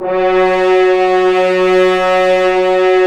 Index of /90_sSampleCDs/Roland L-CD702/VOL-2/BRS_F.Horns 1/BRS_FHns Ambient
BRS F.HRNS07.wav